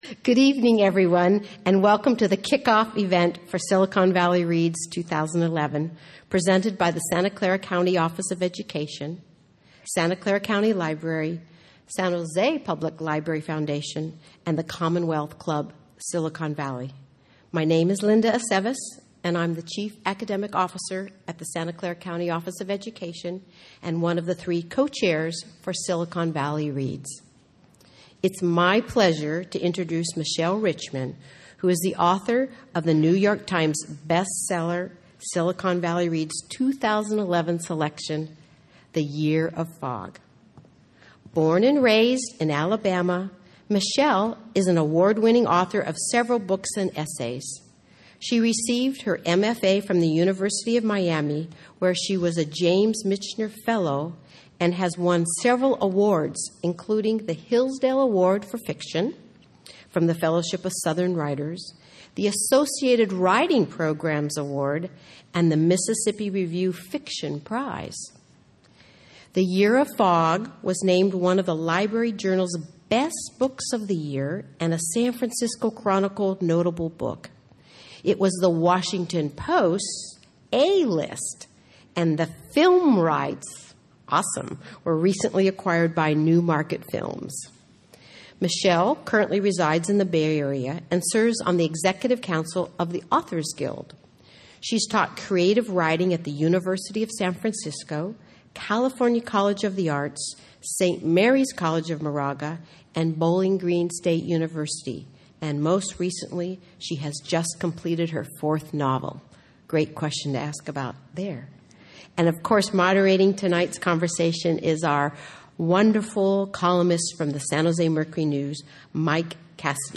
The story continues as Abby tries to understand her memories and uncover the truth. Location: Campbell Heritage Theatre, 1 West Campbell Ave., CampbellTime: 7 p.m. doors open, 7:30 p.m. program, 8:30 p.m.